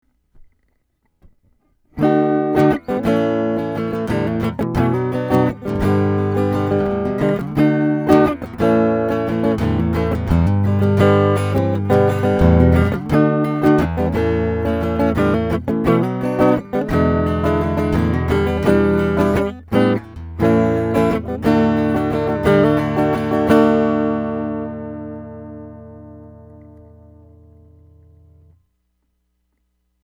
I put together a couple of quick audio clips to demonstrate the difference between going direct into my DAW vs going through the ProDI.
Guitar into the ProID then into the DAW
But even plugged in, there’s a detectable (at least to my ears) muffling of the tone, whereas with the ProDI, the guitar sounds richer.
I realize that with these recordings the differences are subtle at best.